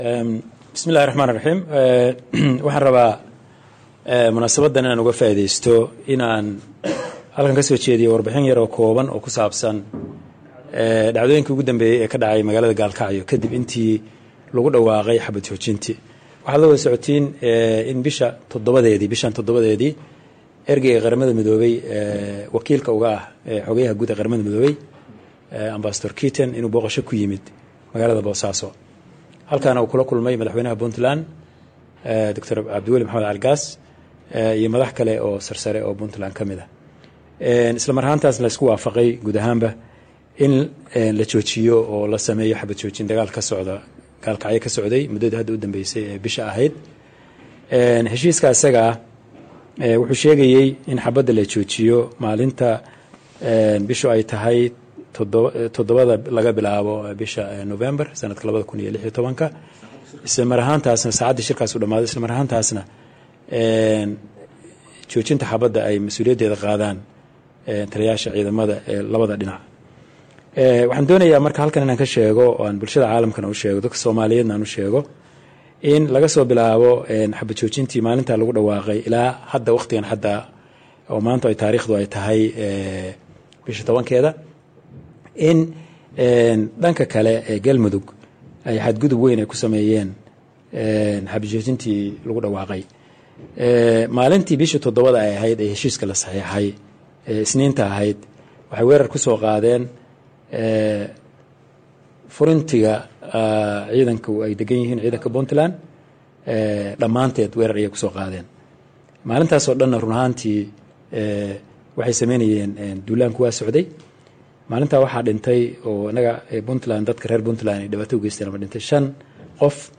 Wasiirka wasaaradda Deegaanka, Duur joogta iyo Dalxiiska Dowladda Puntland Dr.Cali C/lahi warsame,
Dhagayso Dr Cali Cabdulaahi Warsame